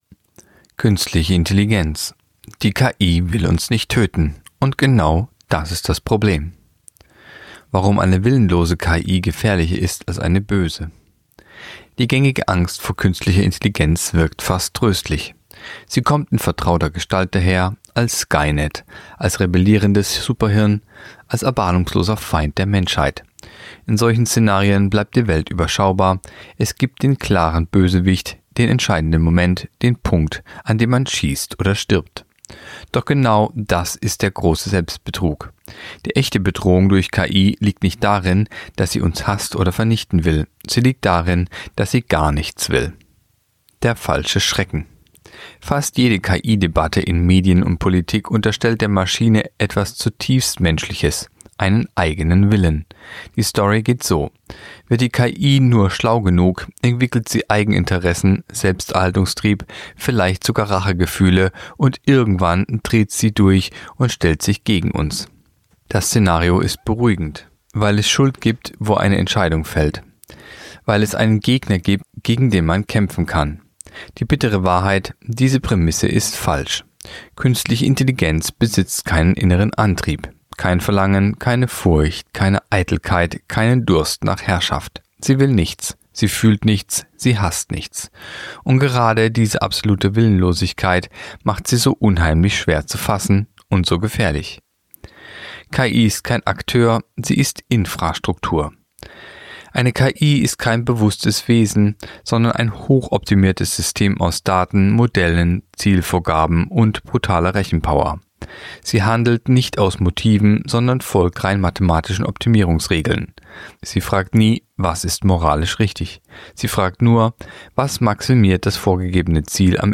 Kolumne der Woche (Radio)Die KI will uns nicht töten – und genau das ist das Problem